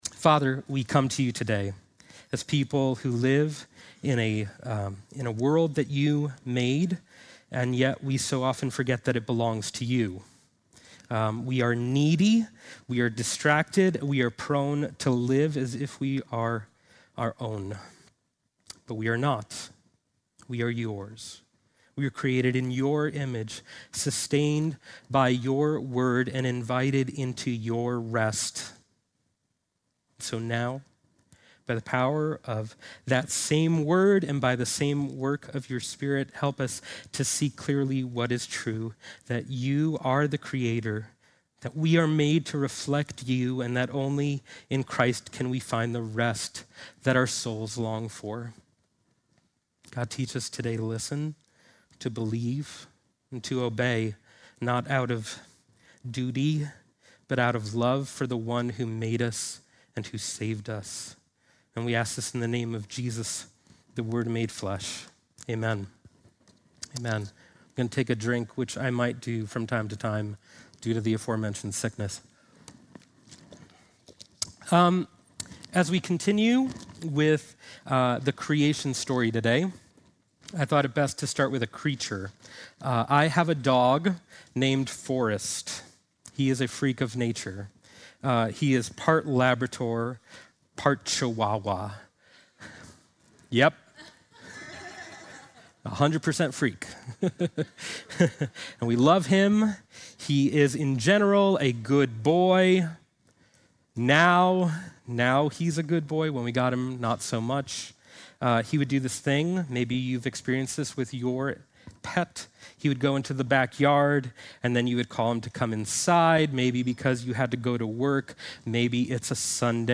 Sunday morning message August 3